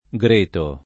greto [ g r % to ] s. m.